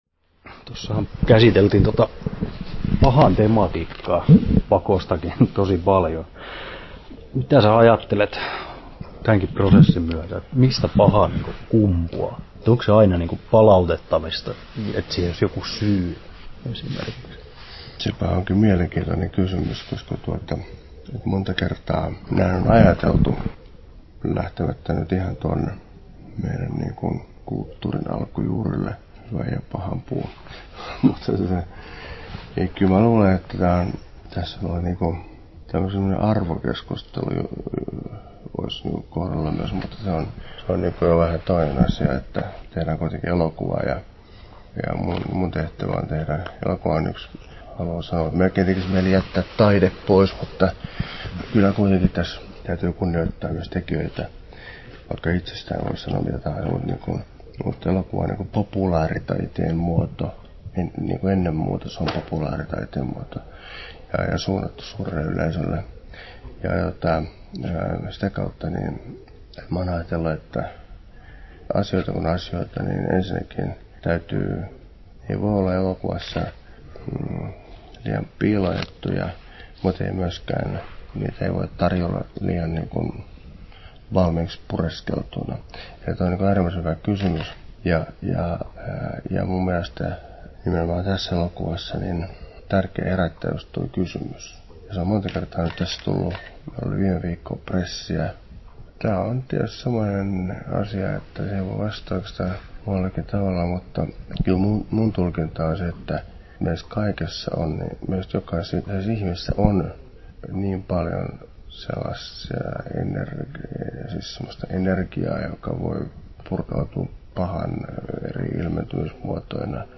Olli Saarelan haastattelu Kesto